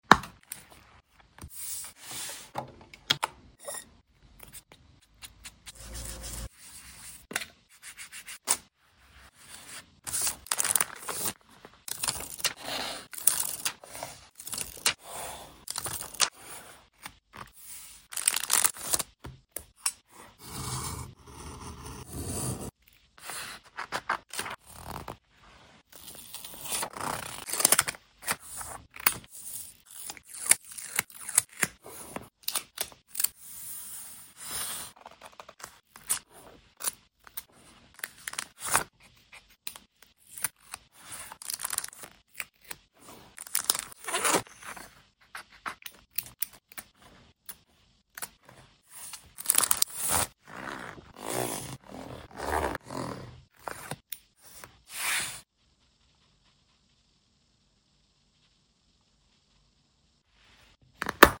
ASMR journal Green Window👒🌿🪟🪴✨💚 Stickers sound effects free download